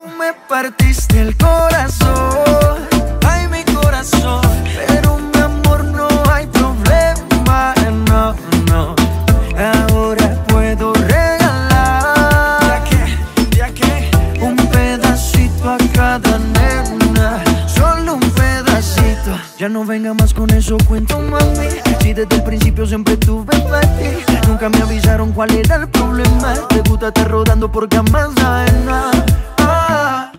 Reguetón